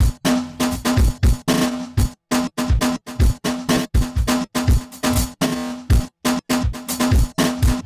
DrumLoop07.wav